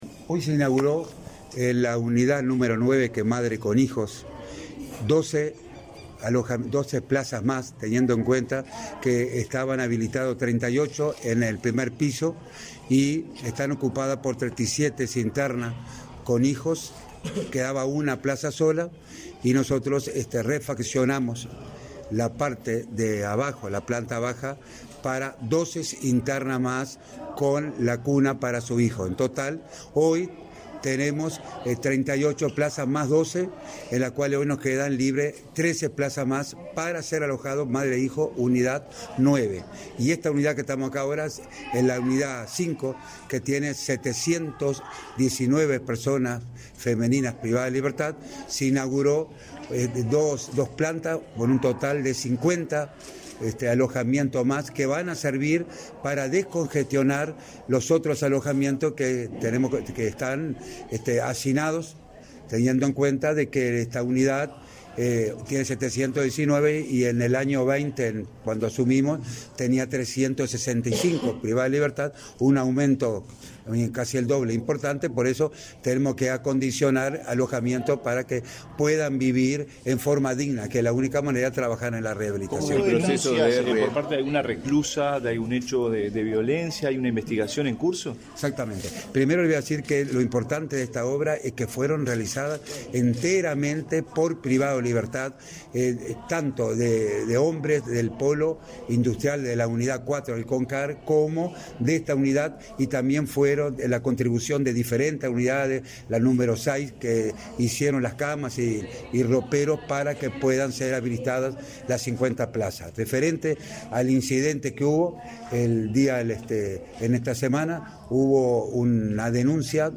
Declaraciones a la prensa del director del INR, Luis Mendoza
Tras el evento, el director del Instituto Nacional de Rehabilitación (INR), Luis Mendoza, realizó declaraciones a la prensa.